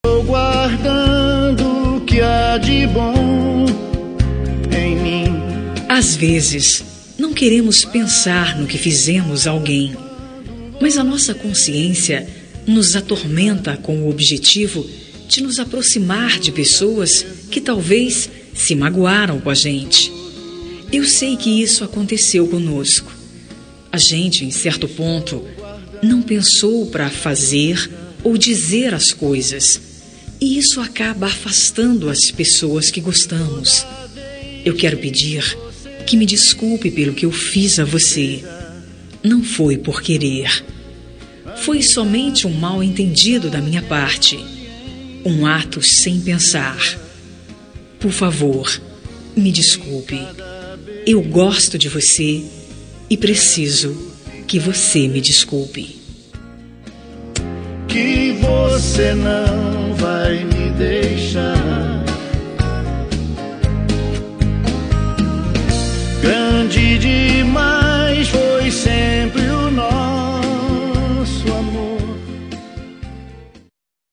Telemensagem de Desculpas – Voz Feminina – Cód: 358